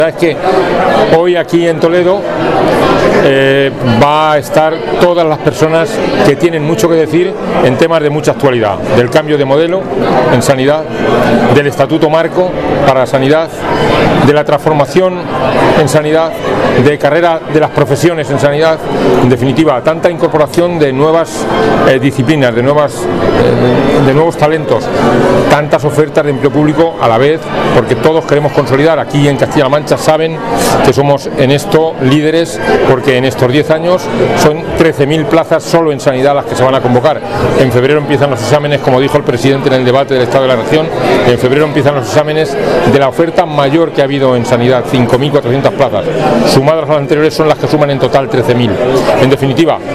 Consejería de Sanidad Miércoles, 22 Octubre 2025 - 5:15pm El consejero de Sanidad ha señalado que hoy aquí en Toledo van a estar todas las personas que tienen mucho que decir en temas de mucha actualidad: del cambio de modelo en sanidad, del Estatuto marco para la sanidad, de la transformación en sanidad, de carrera de las profesiones en sanidad, en definitiva, tanta incorporación de nuevas disciplinas, de nuevos talentos de tantas ofertas de empleo público a la vez, porque todos queremos consolidar personal. Aquí, en Castilla-La Mancha somos en esto líderes porque en estos diez años son 13.000 plazas solo en sanidad las que se van a convocar.